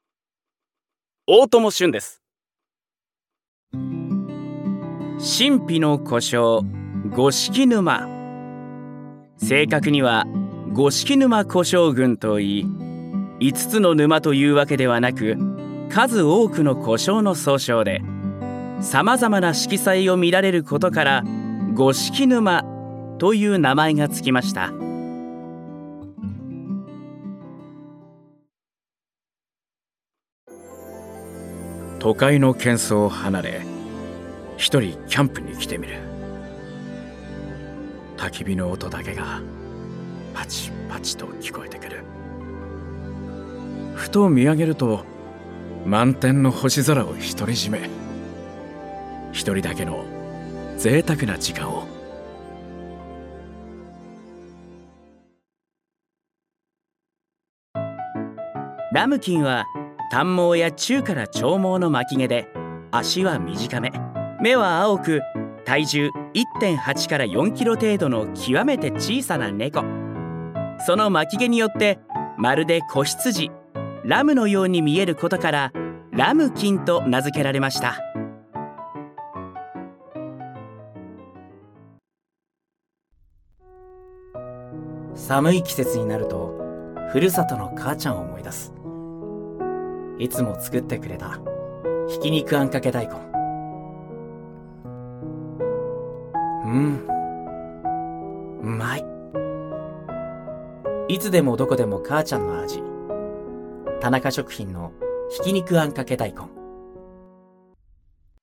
ナレーション